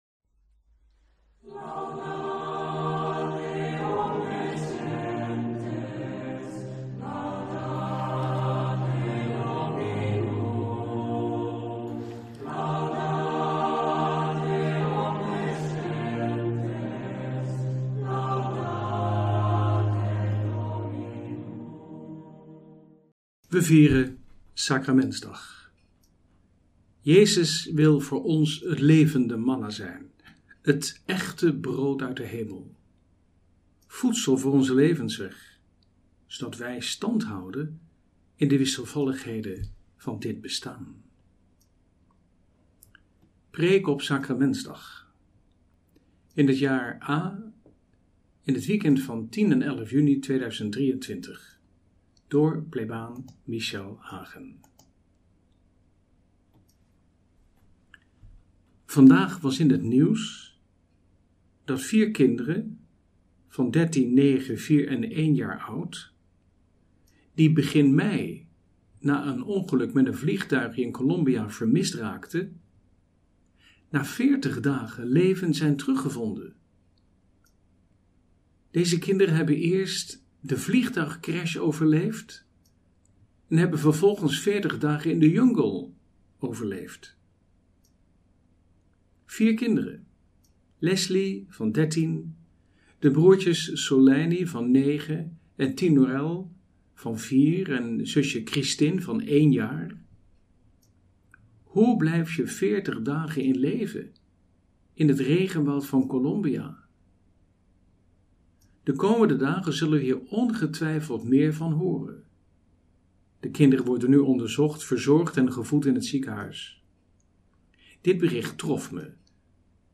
Homilie